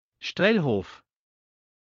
Strelhof_Pronounce.ogg